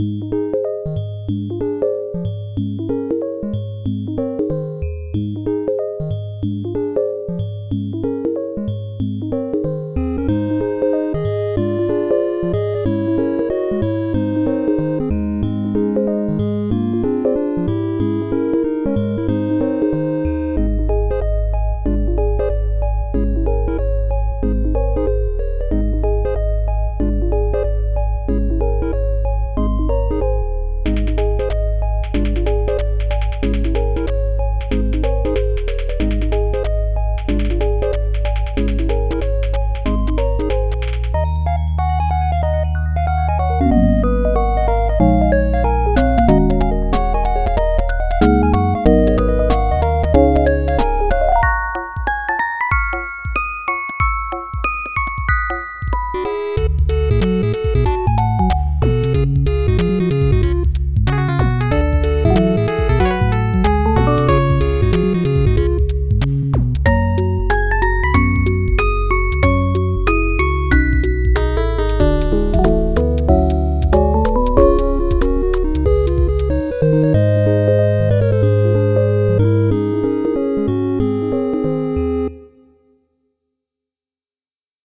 Unused remix of the main theme.
This file is an audio rip from a(n) wii game.